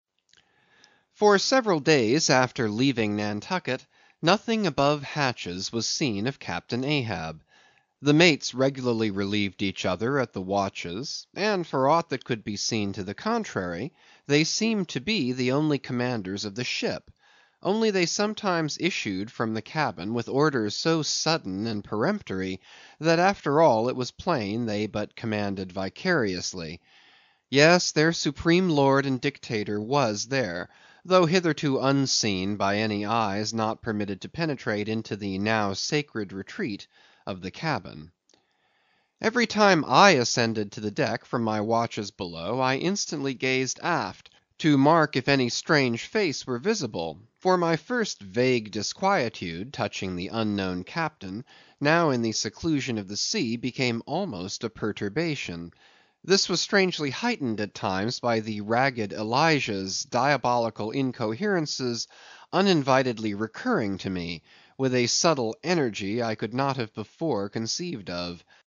英语听书《白鲸记》第159期 听力文件下载—在线英语听力室